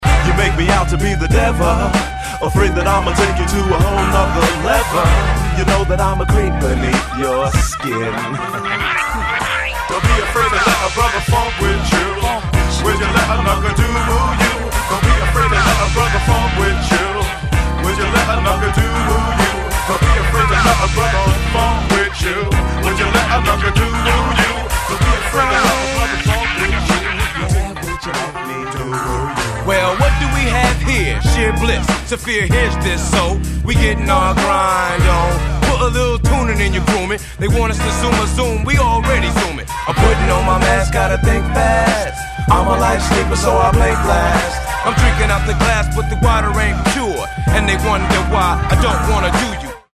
feinster Funk